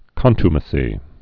(kŏnt-mə-sē, -ty-)